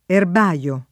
erbaio [ erb #L o ] s. m.; pl. erbai